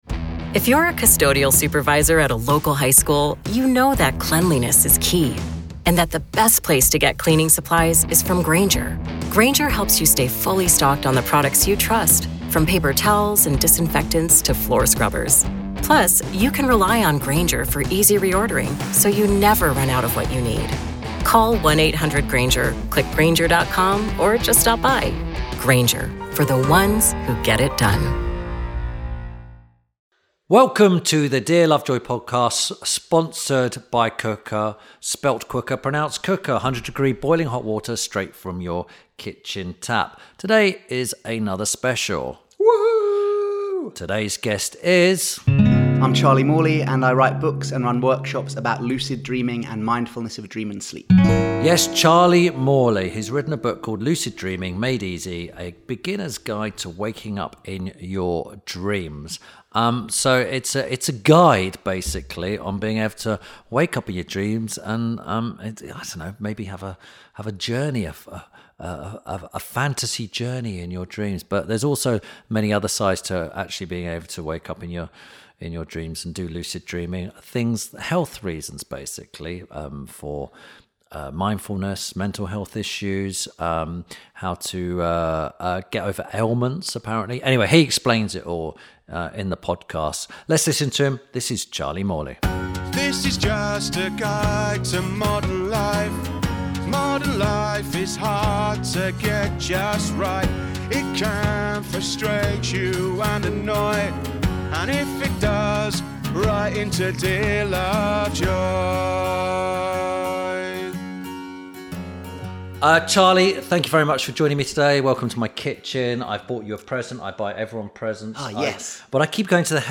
- INTERVIEW SPECIAL